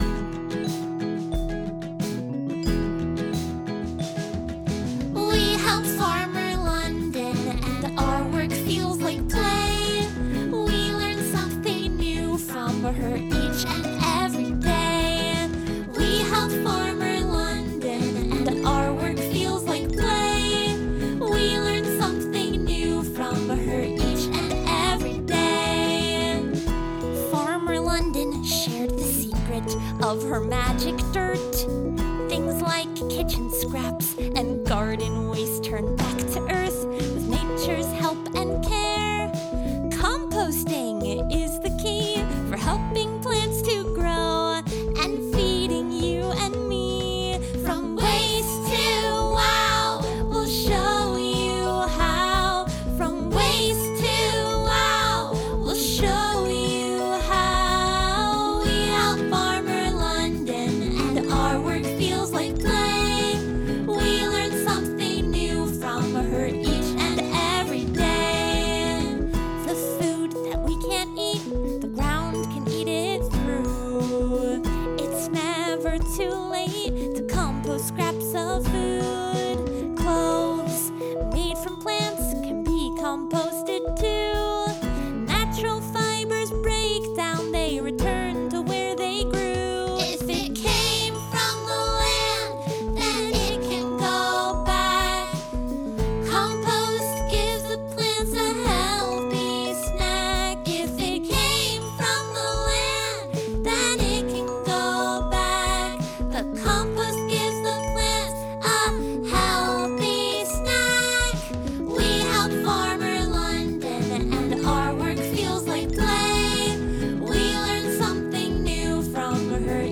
will have children singing along